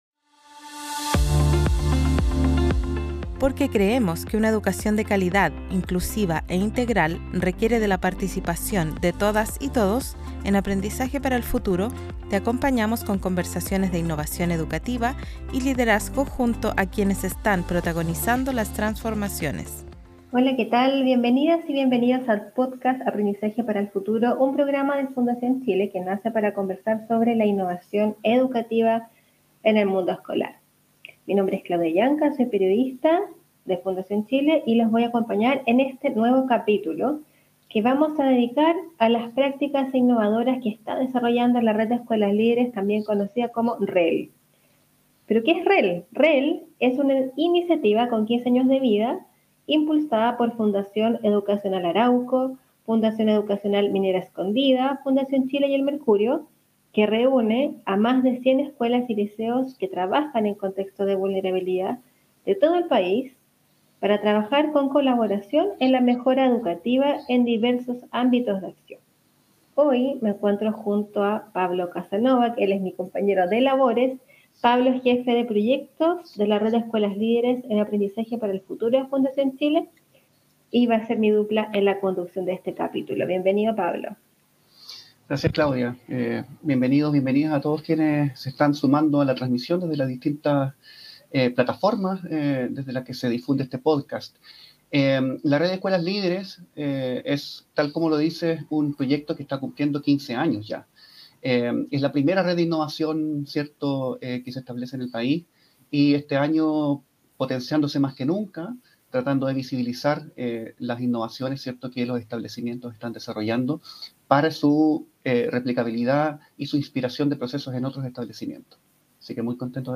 Podcast ENTREVISTADOS/AS